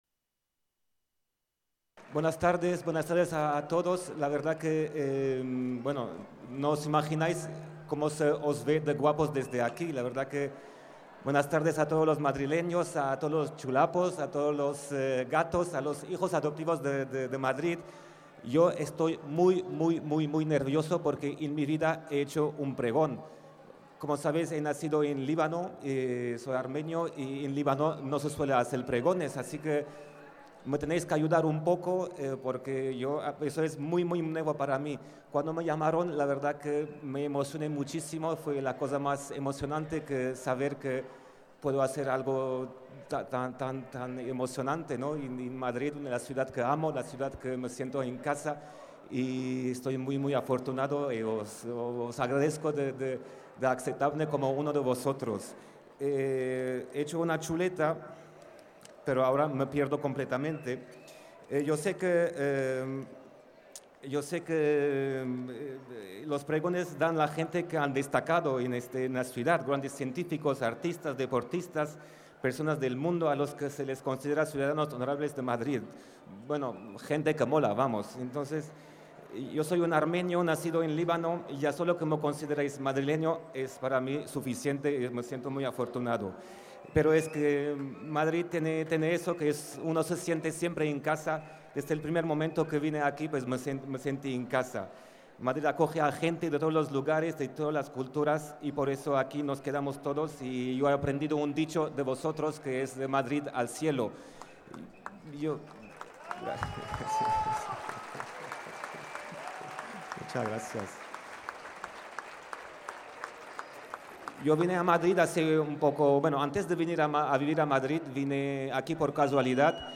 Ara Malikian abre las fiestas de San Isidro 2015 tocando para todos los congregados en la Plaza de la Villa
Nueva ventana:Ara Malikian, lectura del pregón